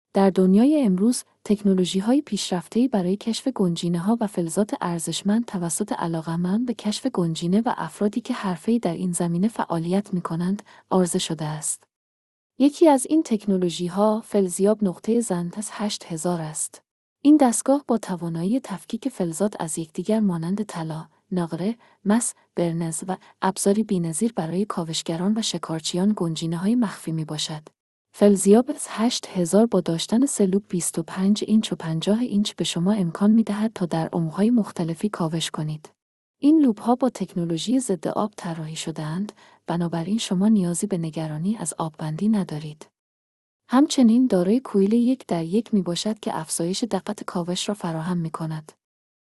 • سخنگو